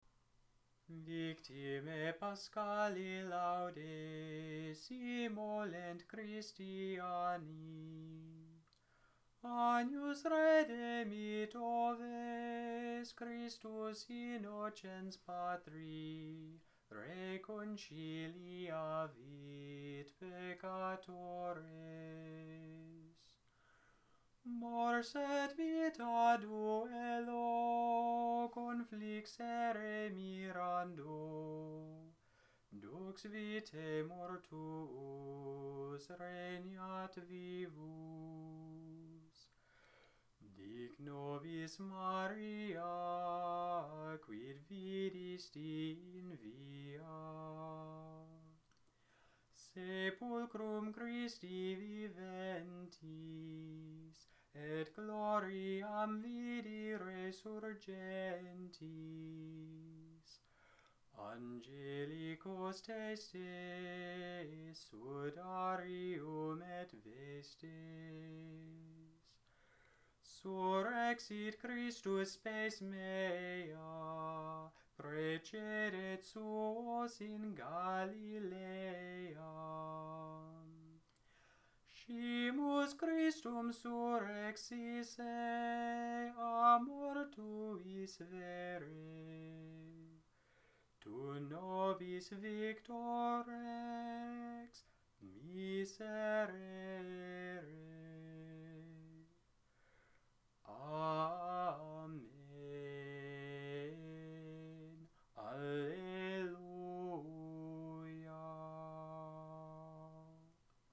Gregorian, Catholic Chant Victimae Paschali Laudes
Use: Sequence, at Mass